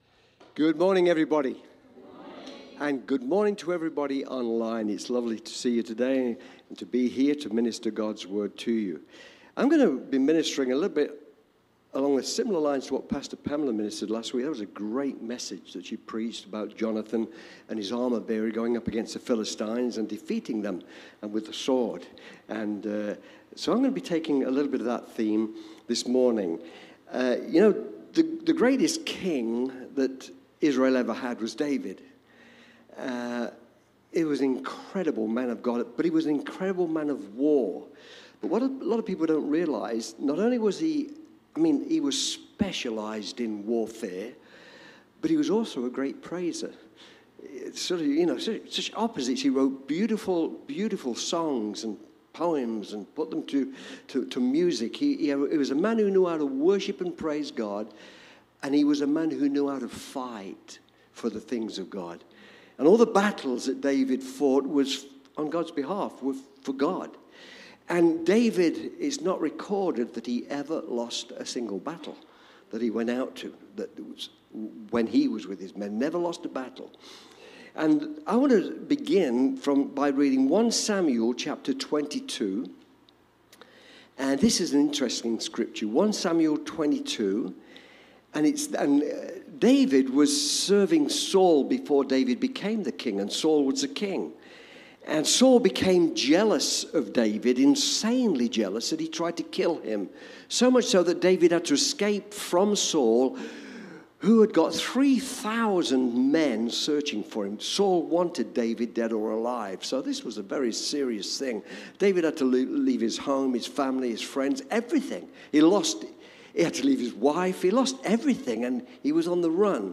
Cityview-Church-Sunday-Service-Make-a-Stand.mp3